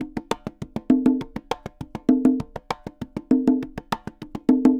Congas_Salsa 100_2.wav